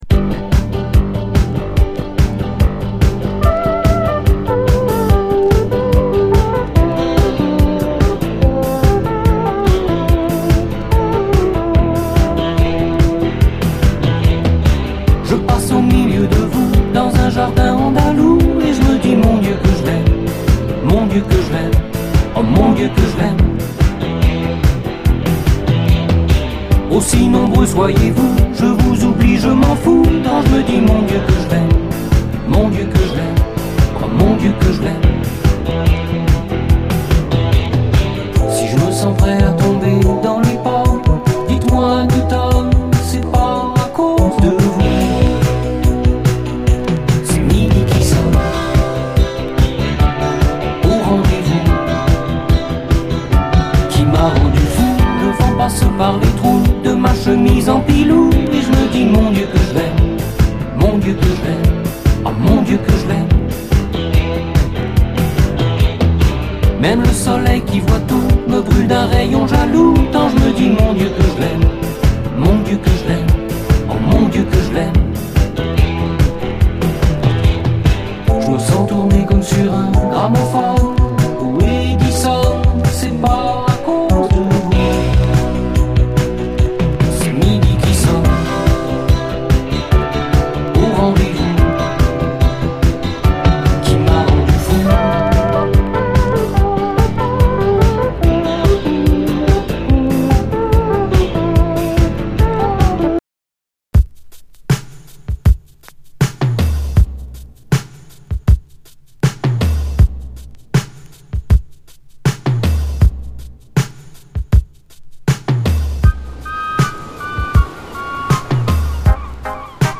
80's～ ROCK, WORLD, ROCK
ヒネクレ・ポップ度の高いフレンチ・ニューウェーヴ野郎！AOR〜ニューウェーヴ〜ディスコを軽やかに横断！